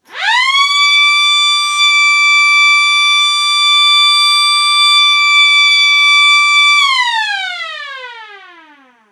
Sirena elettromeccanica media in alluminio.
Grande pressione acustica e basso consumo elettrico.